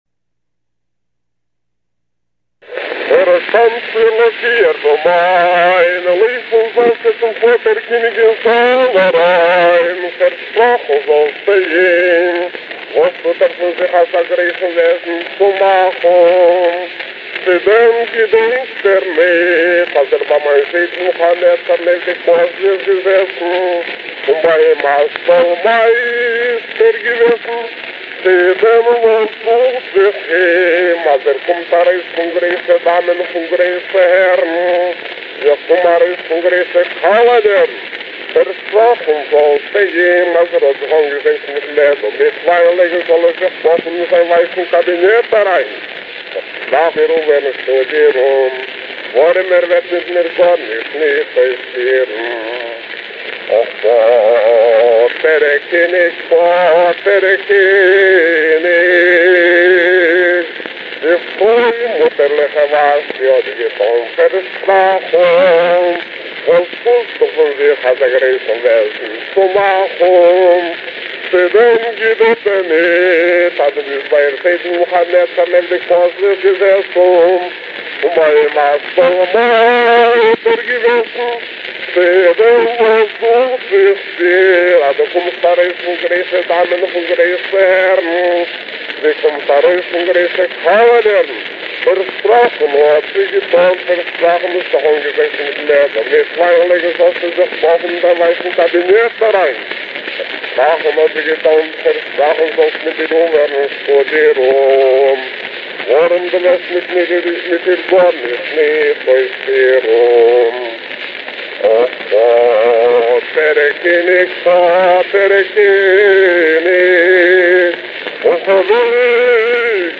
זעקס: (א) אַ שטיקל פון אחשורוש שפּיל (פאַרשריבן אין קאַלינקאָוויטש, ווײַסרוסלאַנד, אפּנים אין 1934) — זעט אַ שייכותדיקן וואַריאַנט אין דעם פאָלגנדיקן פראַגמענט (עס שטימט ווייניק, אָבער העלפט זיך בעסער צו אָריענטירן)